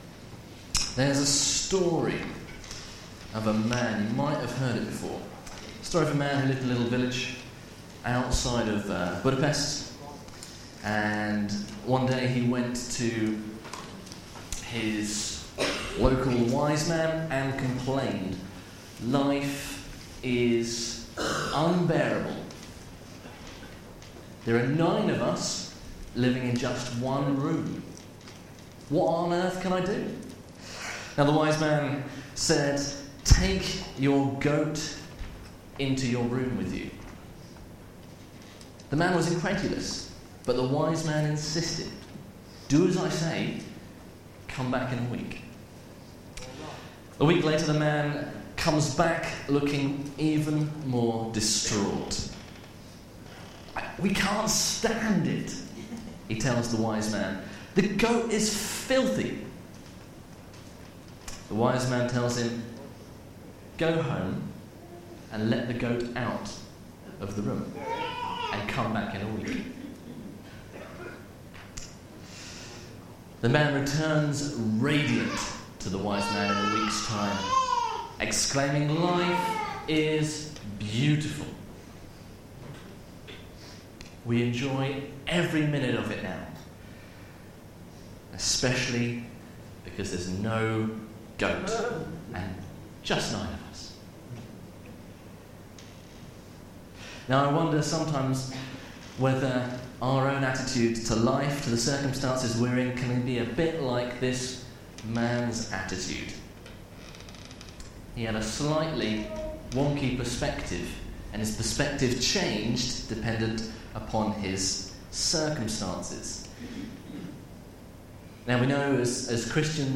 Media for Sunday Service
His love endures forever Sermon